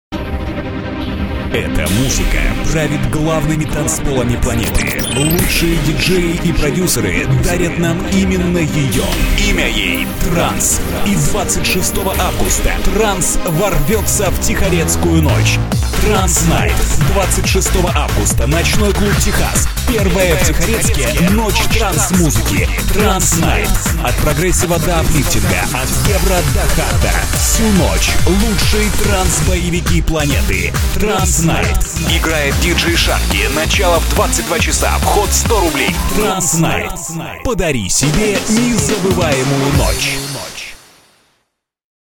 Ролик изготовленный для рекламной акции вечеринки "Trance Night".